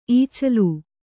♪ īcalu